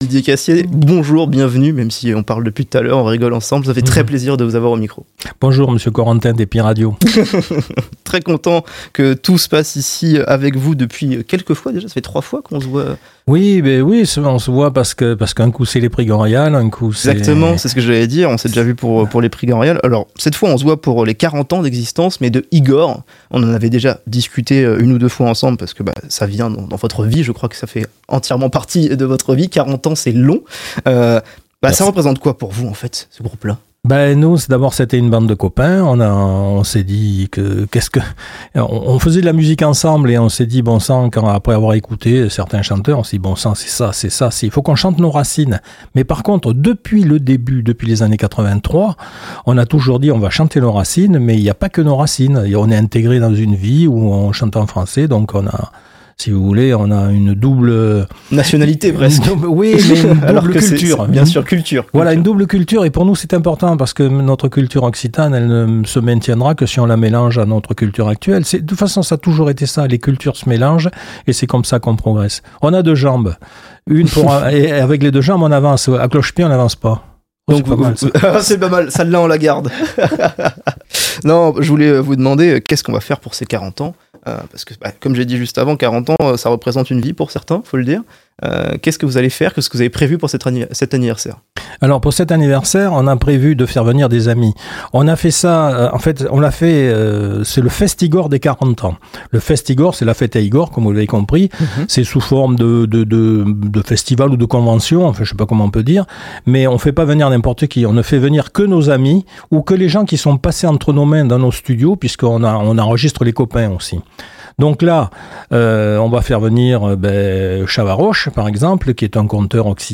Les interviews Happy Radio – Fest’IGOR